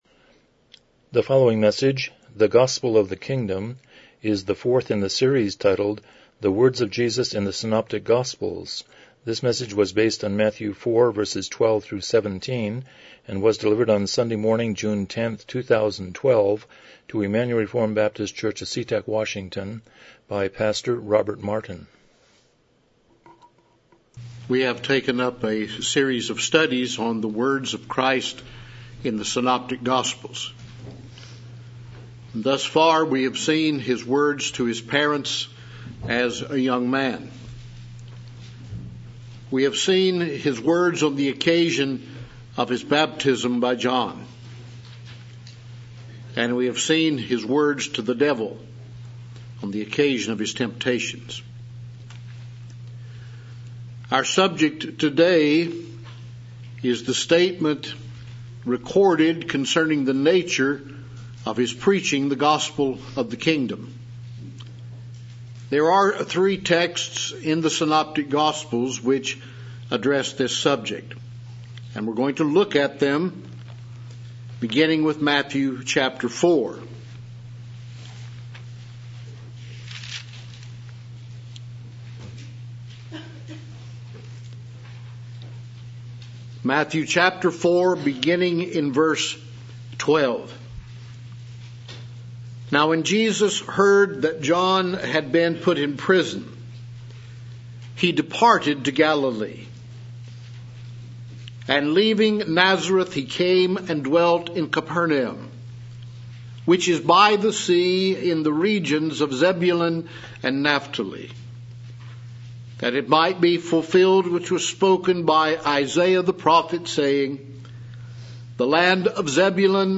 Passage: Matthew 4:12-17 Service Type: Morning Worship